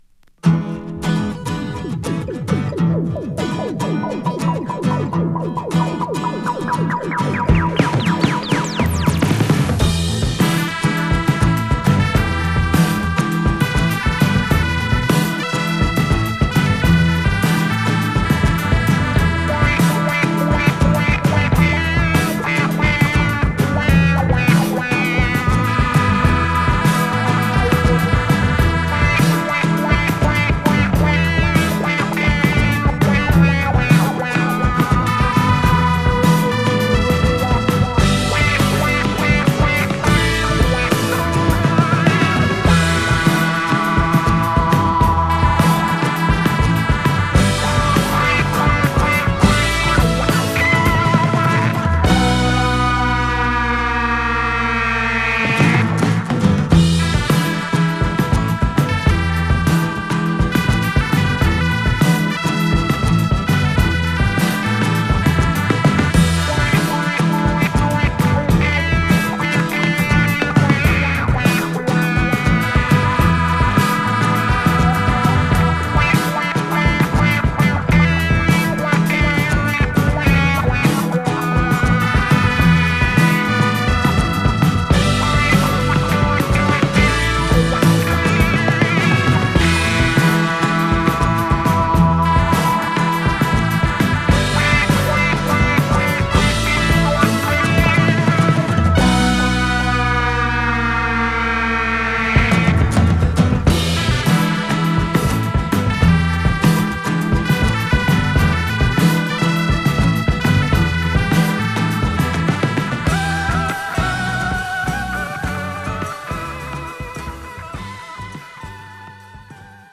> PSYCHEDELIC/PROGRESSIVE/JAZZ ROCK